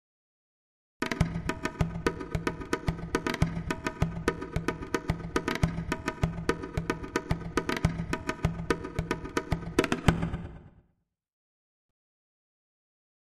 Drums Asian Dance Beat 1 - Longer